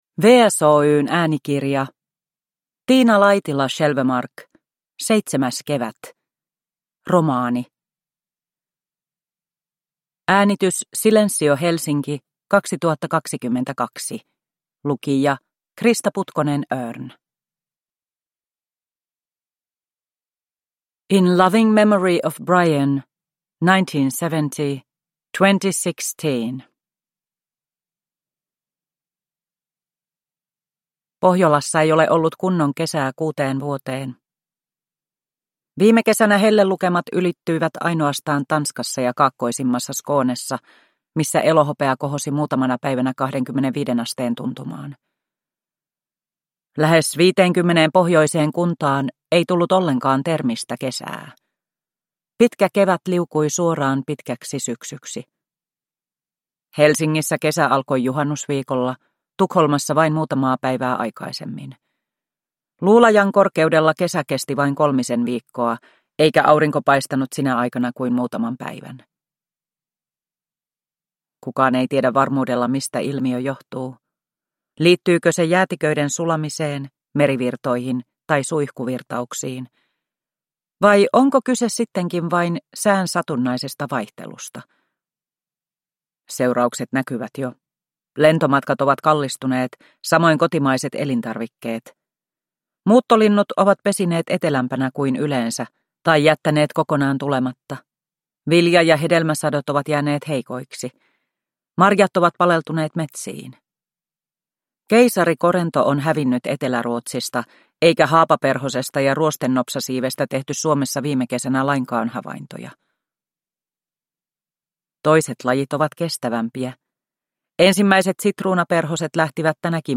Seitsemäs kevät – Ljudbok – Laddas ner